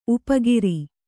♪ upa giri